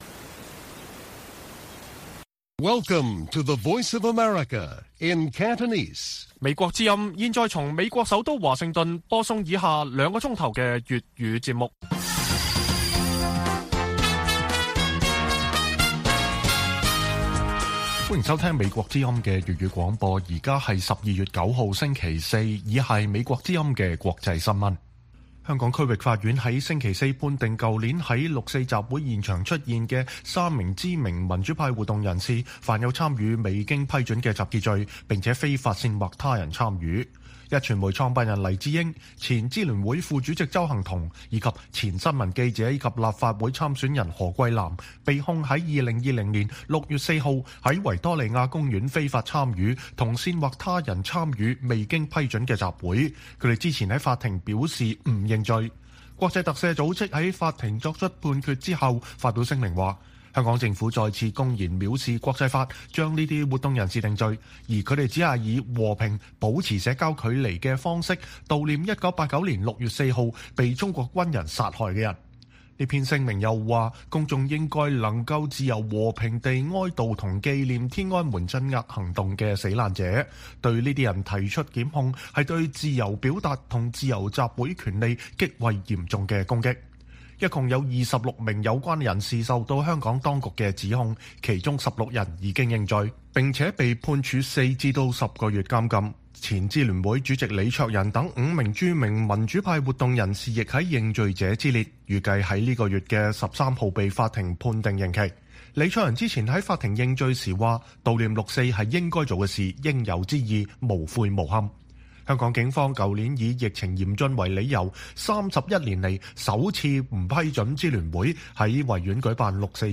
粵語新聞 晚上9-10點: 香港法庭判定六四集會案黎智英等三人罪成